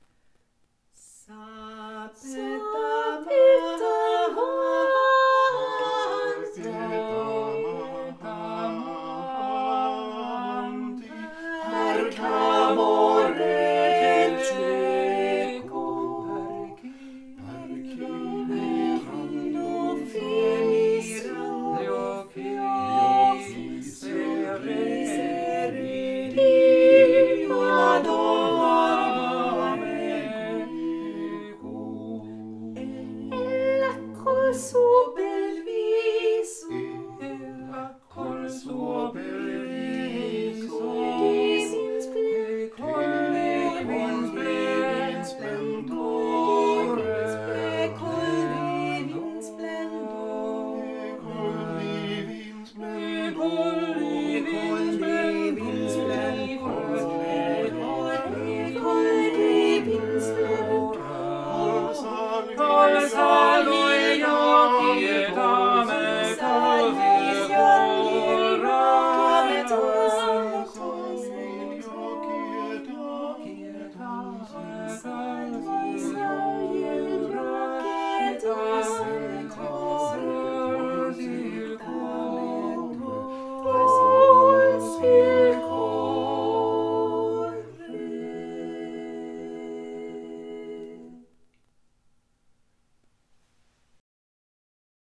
Vokalensemblen KALK